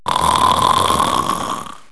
Loud Snore Sound Effect Free Download
Loud Snore